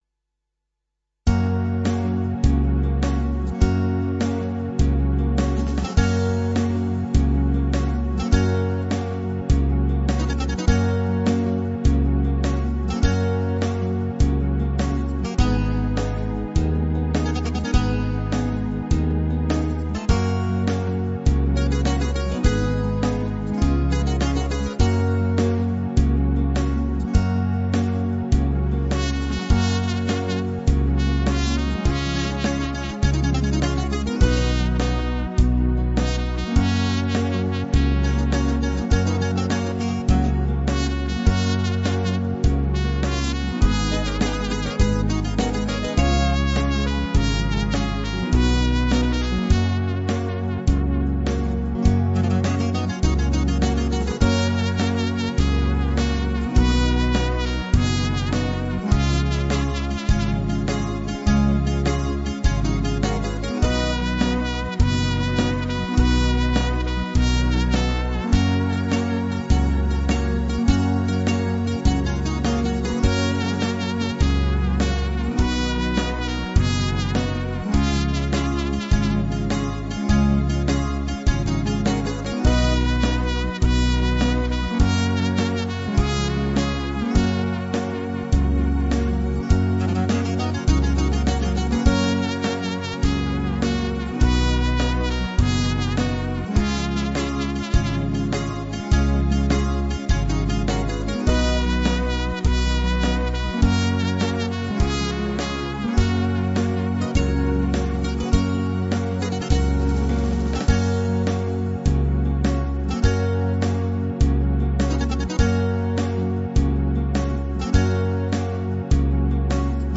Hasaposervika